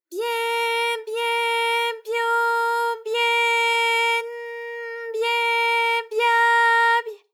ALYS-DB-001-JPN - First Japanese UTAU vocal library of ALYS.
bye_bye_byo_bye_n_bye_bya_by.wav